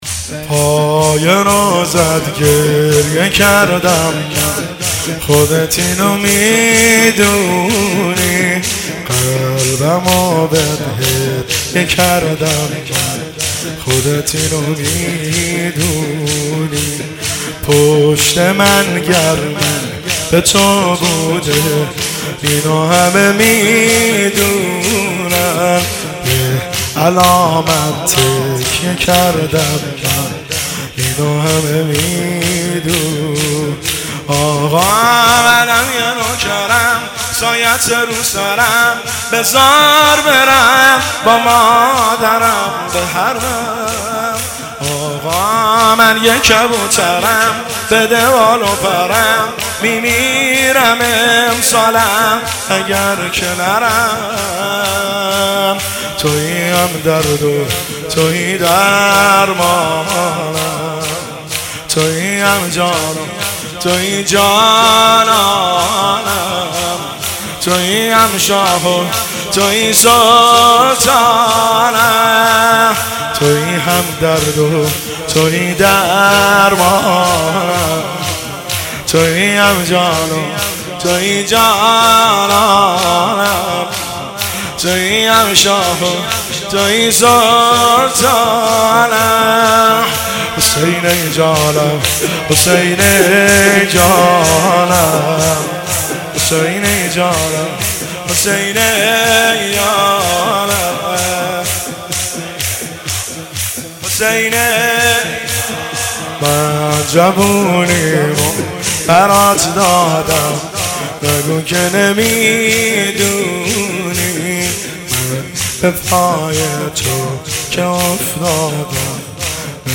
تولید شده: هیئت عاشقان قمر بنی هاشم تهران